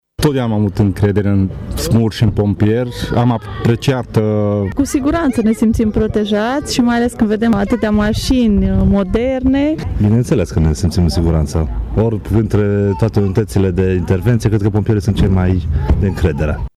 Părinții celor mici, prezenți la sediul ISU Mureș, spun că pompierii sunt în topul încrederii românilor: